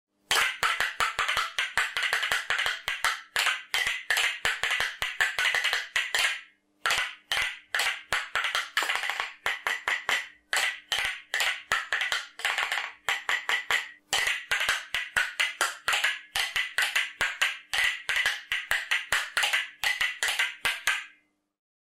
Звук соло на деревянных музыкальных ложках